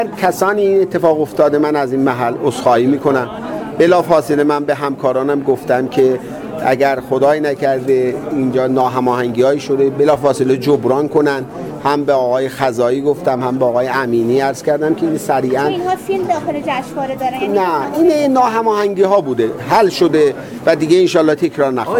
به گزارش ایکنا؛ محمدمهدی اسماعیلی وزیر فرهنگ و ارشاد اسلامی در حاشیه آیین تجلیل از روزنامه‌نگاران تراز انقلاب (قلم مقدس) در جمع خبرنگاران و ضمن عذر خواهی از بروز حواشی اخیر جشنواره فیلم فجر گفت: «اگر برای هر کسی این اتفاق افتاده من از اینجا عذرخواهی می‌کنم. بلافاصله به همکاران گفتم که اگر خدای نکرده ناهماهنگی‌هایی شده جبران کنند. این حواشی به دلیل ناهماهنگی‌هایی بوده که حل شده و تکرار نخواهد شد.»